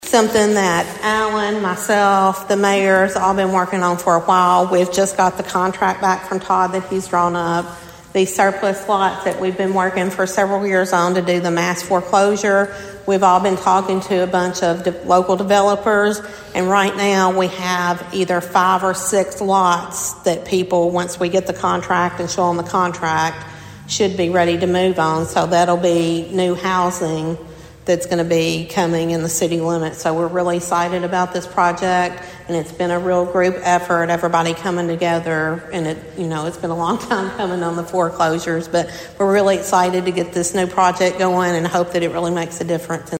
At the City Council meeting on Monday night